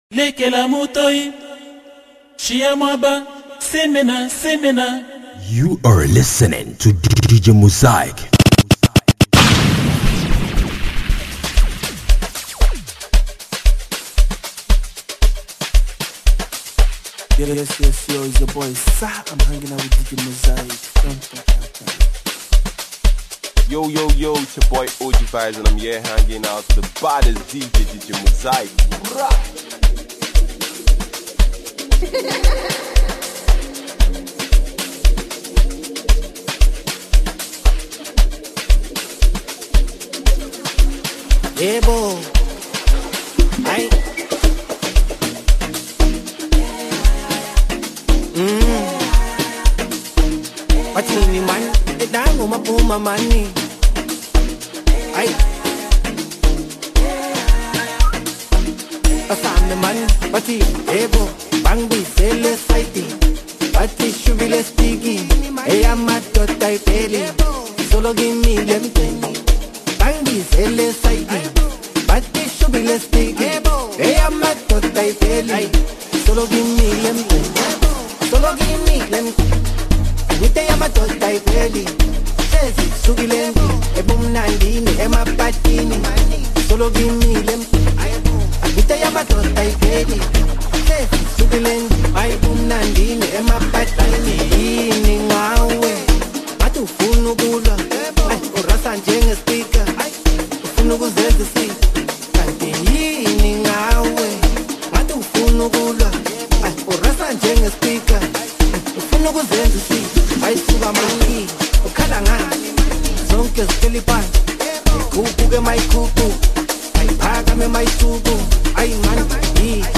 mixtape
Genre: Mixtape